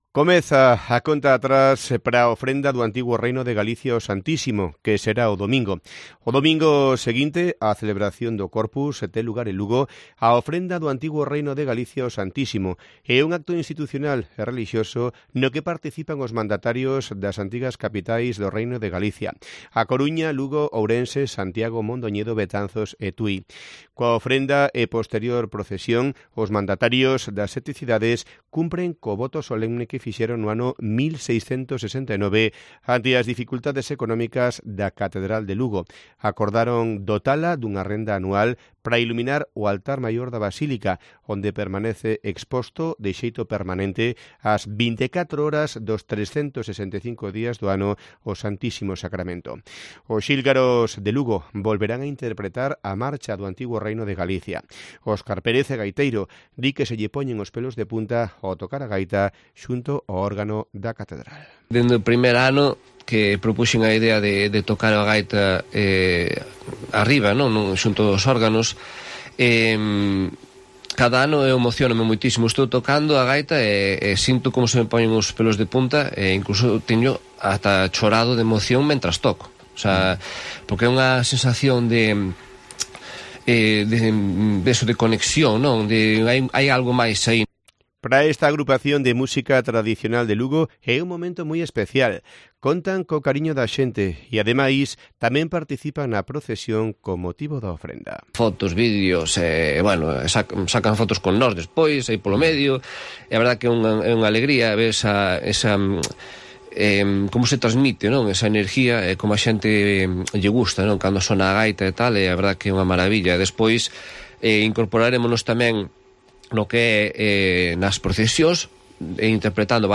Nos contaba en COPE Lugo que, una década después, todavía se le ponen los "pelos de punta" cuando interpreta la pieza en comunión con el órgano de la propia Catedral de Lugo.